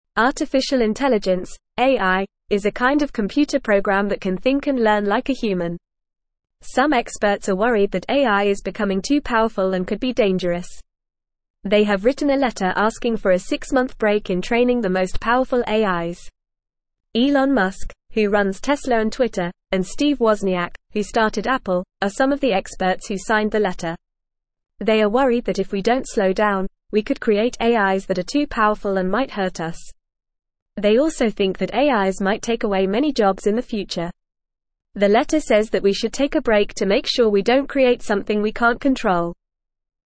Fast
English-Newsroom-Beginner-FAST-Reading-Experts-Ask-for-Break-in-AI-Training.mp3